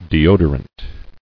[de·o·dor·ant]